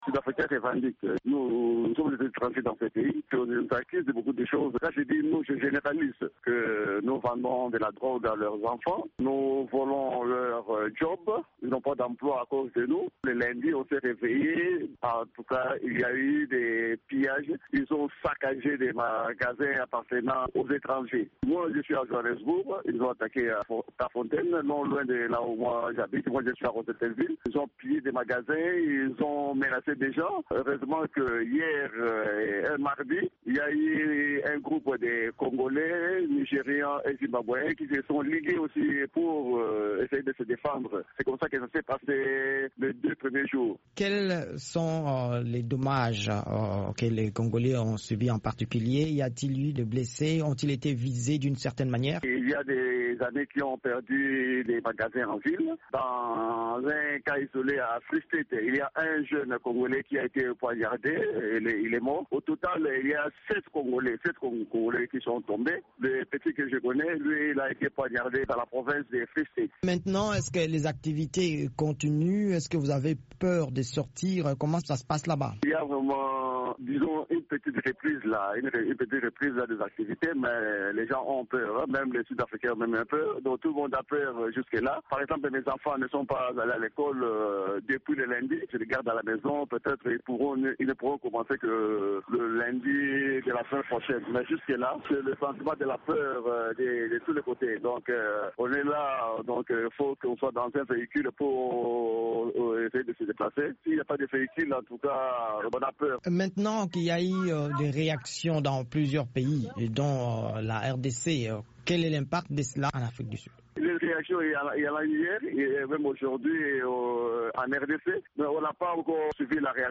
Réaction d'un Congolais résident à Johannesburg